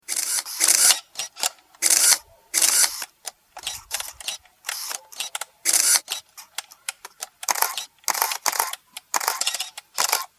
Press Conference
Television Press Conference